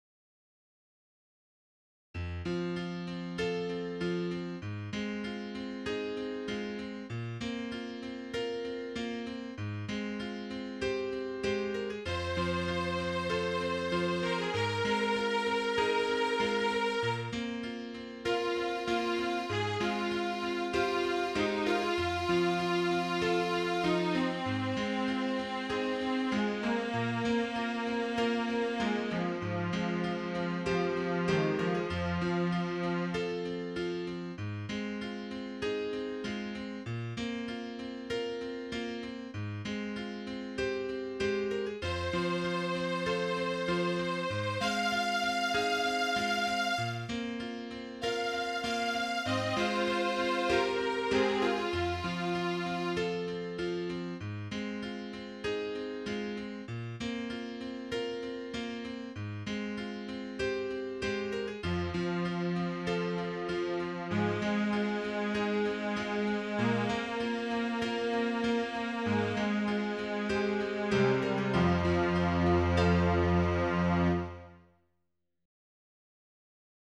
Title Dainty Opus # 22 Year 2001 Duration 00:01:17 Self-Rating 2 Description Just a simple little tune. mp3 download wav download Files
Solo, Piano, Strings Plays